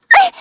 Taily-Vox_Damage_01.wav